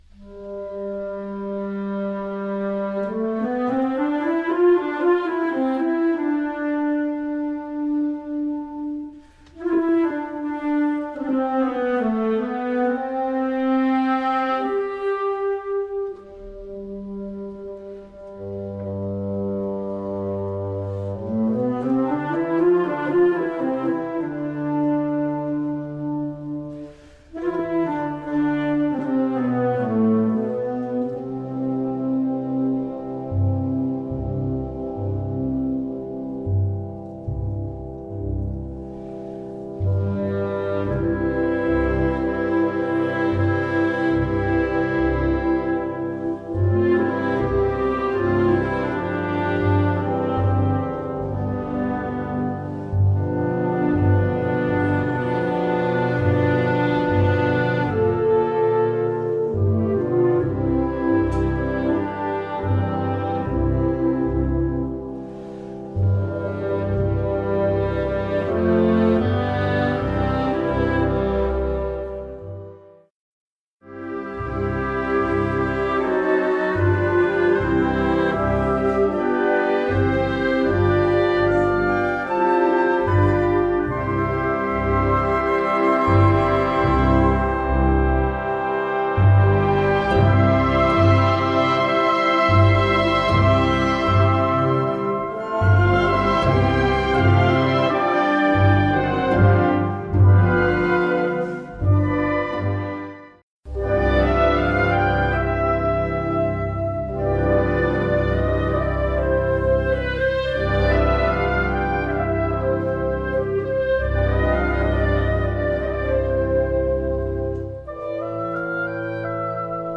orchestre d'harmonie